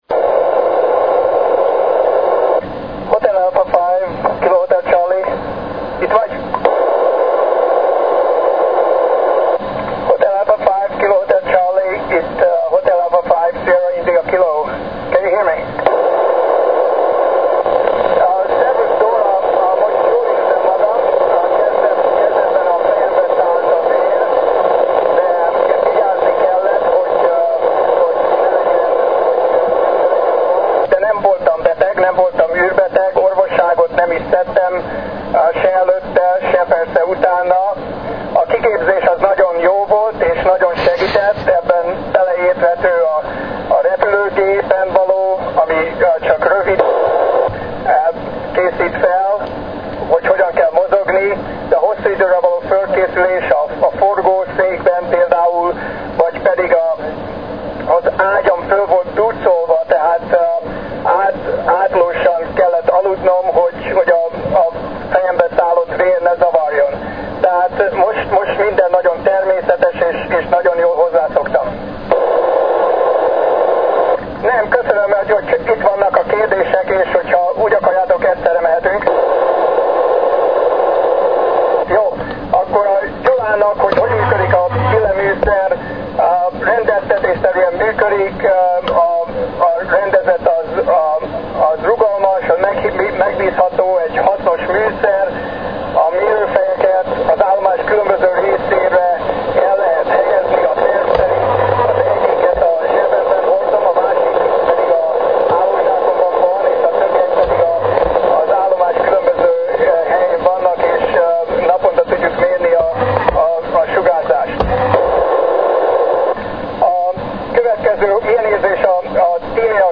Charles Simonyi calling for School contact, and part of school contact in Hungarian (edited to cut out large 'blank spaces')